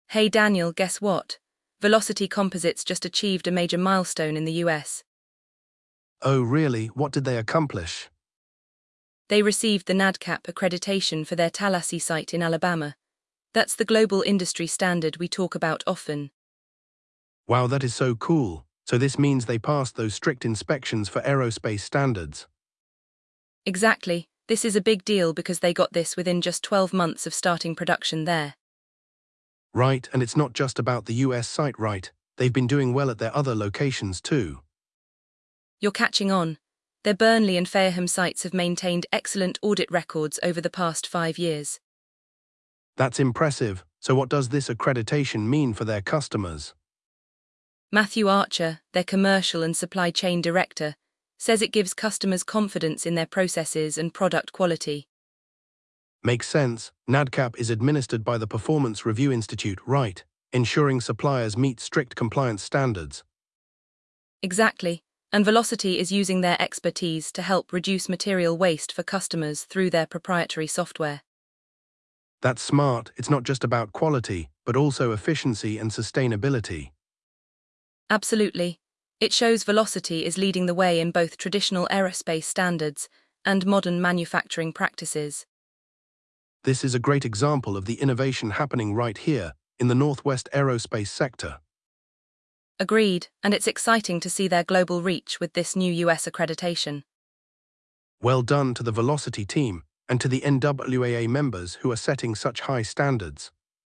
They explore the significance of this milestone within the aerospace industry, highlighting the company's rapid success and consistent performance at their UK sites. The conversation also covers how Velocity is leveraging technology to reduce waste and enhance efficiency while maintaining high-quality standards.